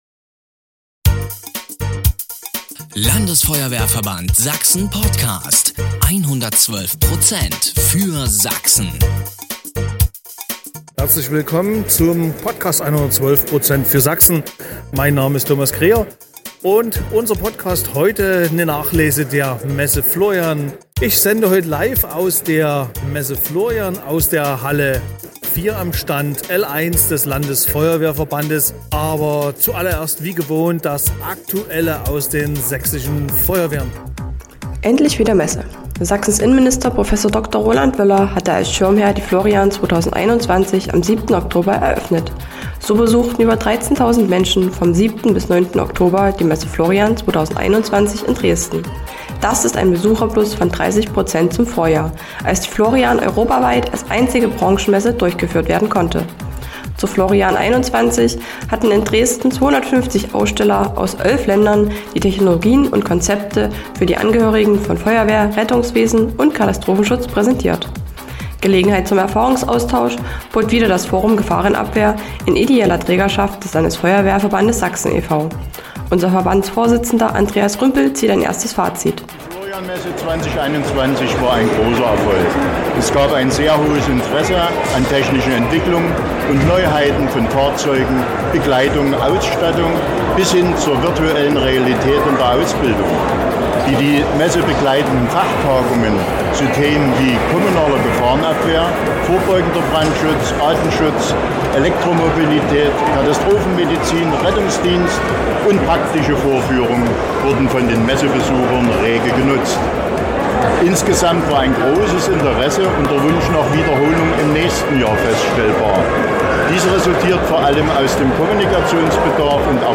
Alle Aufnahmen entstanden live auf dem Messestand des Landesfeuerwehrverbandes Sachsen e.V.